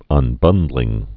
(ŭn-bŭndlĭng)